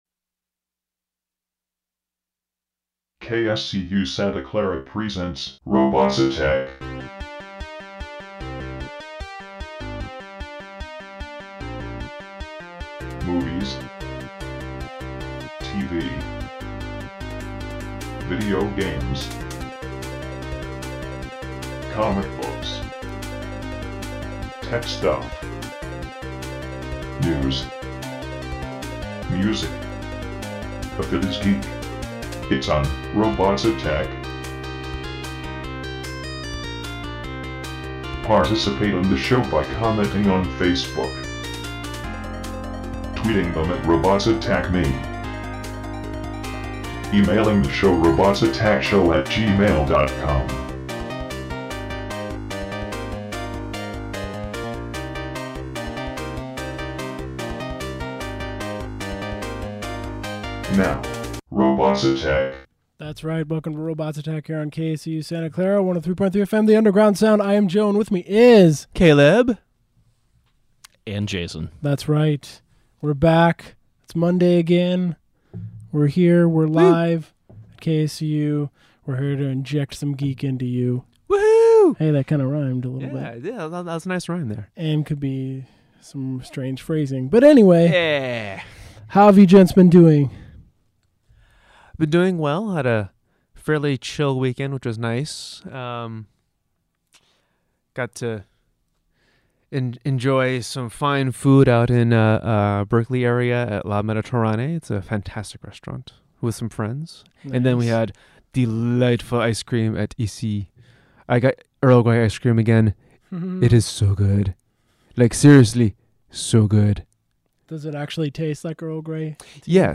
Geek Talk
Listen live Mondays from 7pm to 9pm on 103.3 FM KSCU Santa Clara